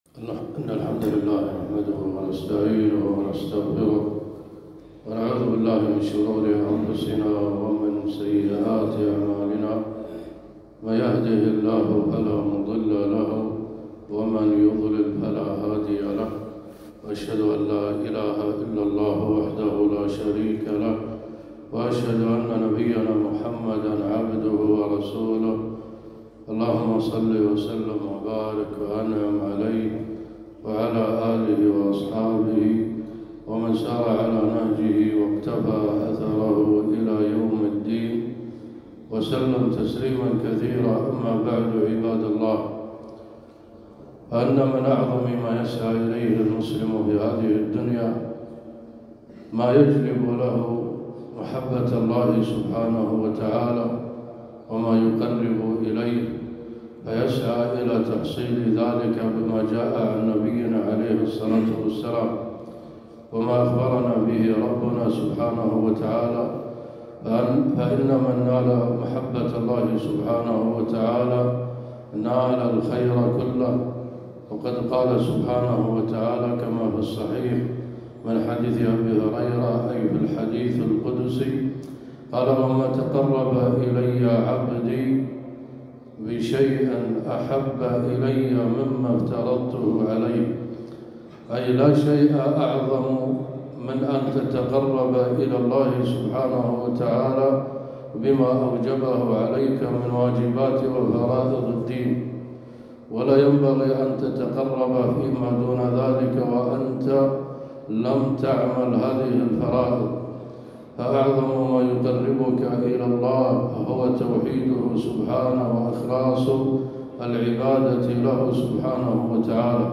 خطبة - فضل صيام شعبان